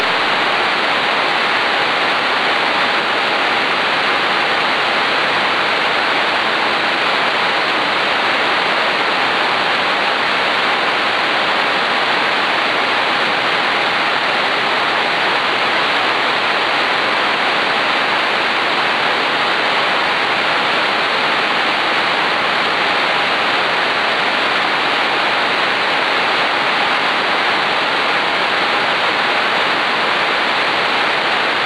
audio from the 114km QSO (682kb 31 sec .WAV file).